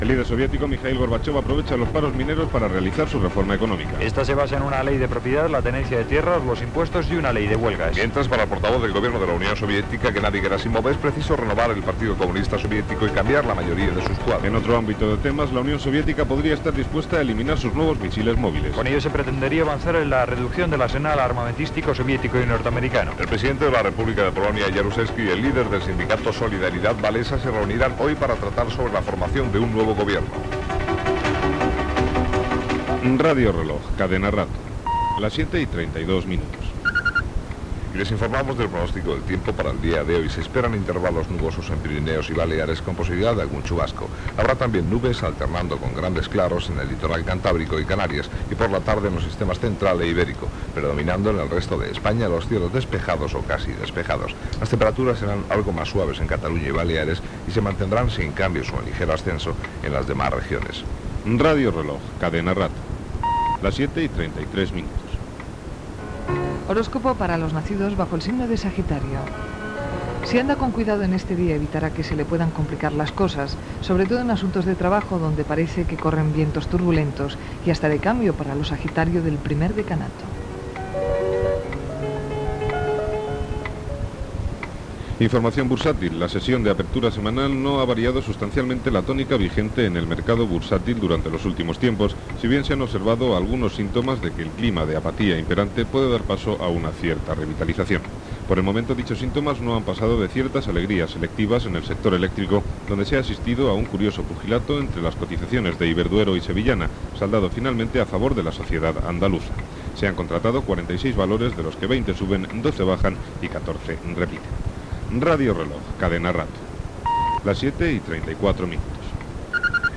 Senyals horaris, indicatiu del programa, equip, resum de notícies espanyoles i internacionals, el temps, identificació, Parlament Europeu, comentari, promoció de Radio Reloj, identificació.
Gènere radiofònic Informatiu